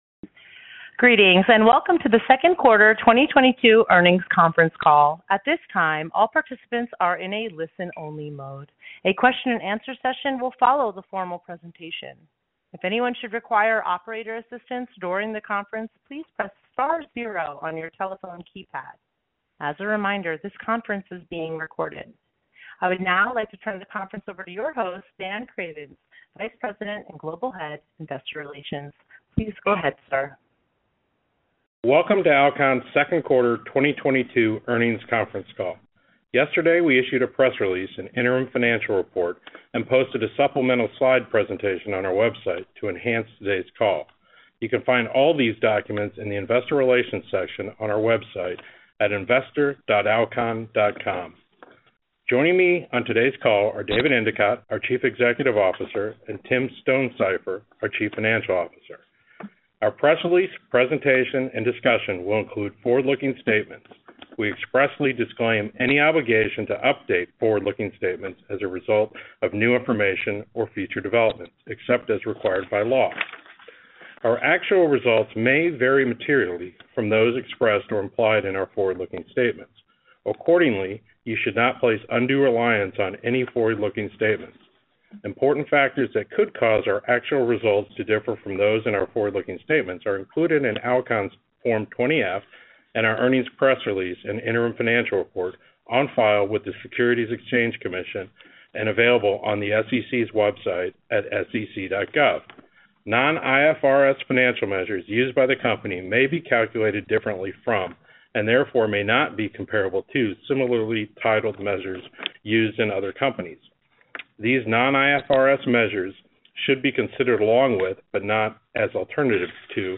Alcon - Alcon’s Second Quarter 2022 Earnings Conference Call